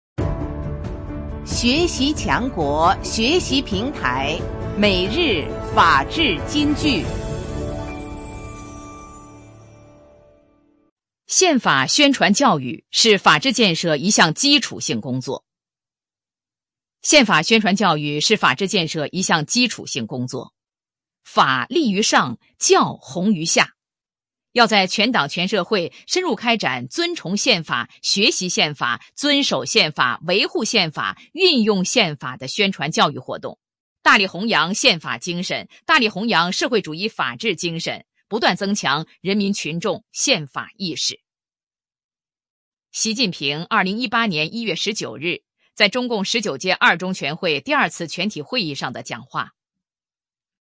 每日法治金句（朗读版）|宪法宣传教育是法治建设 一项基础性工作 _ 学习宣传 _ 福建省民政厅